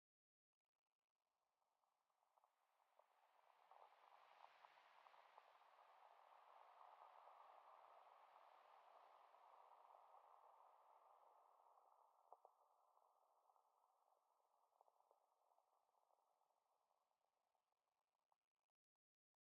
creakysand1.ogg